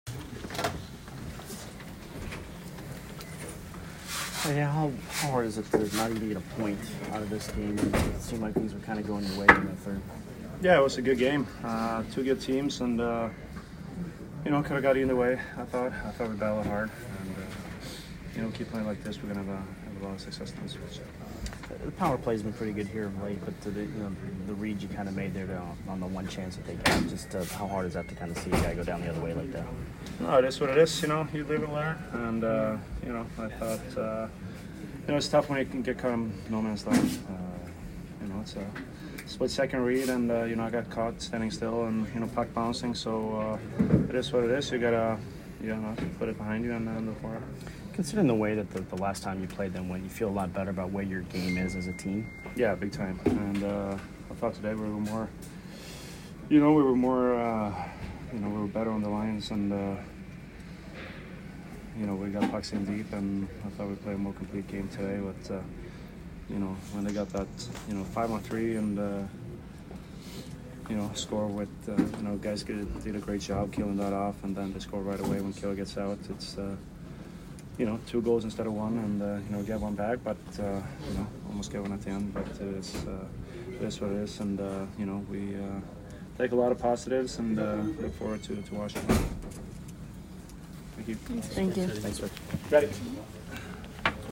Hedman post-game 11/27